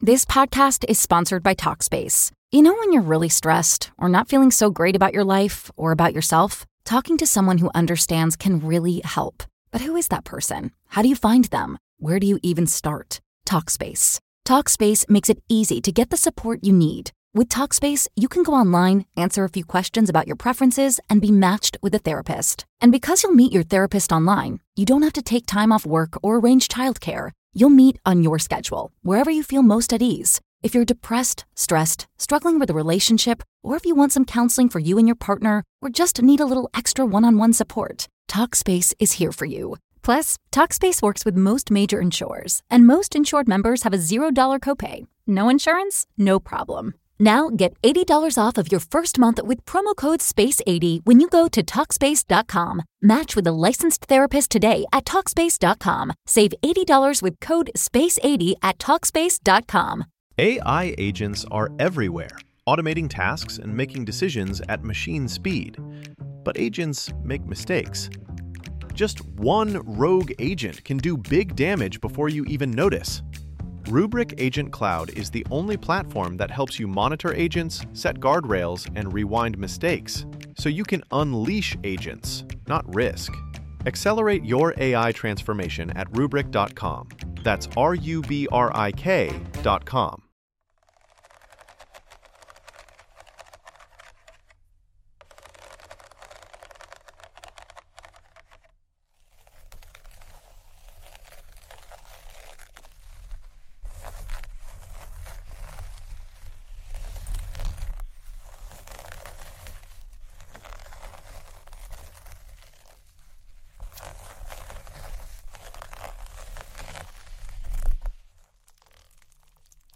A Collection of Tapping & Scratching on Different Bags
Our carefully curated episodes feature soothing whispers, gentle tapping, and immersive binaural sounds designed to calm your mind, improve sleep, and bring balance to your day.
From soft-spoken affirmations to delicate hand movements, we blend ambient triggers and immersive soundscapes to create a space where you can experience the full effects of ASMR.